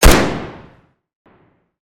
Gunshot.wav